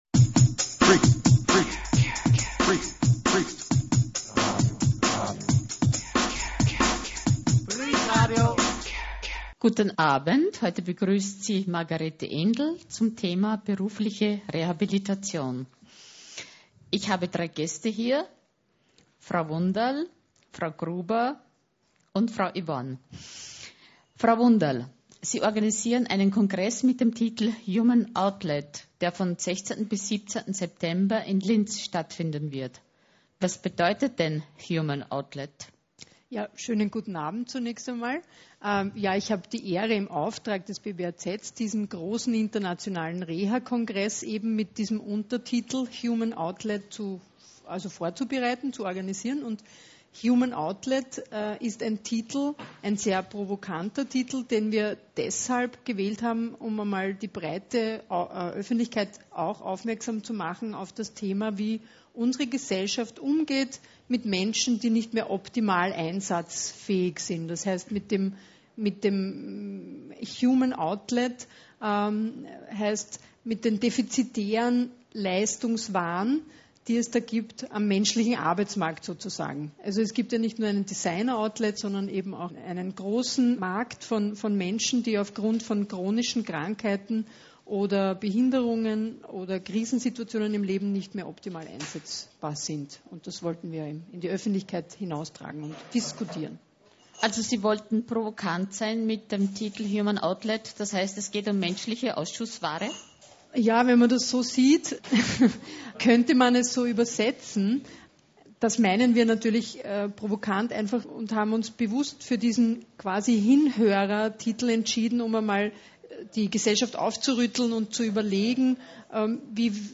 Quelle: INTERNATIONALER REHA KONGRESS Diese Sendung können Sie hier als MP3 herunterladen.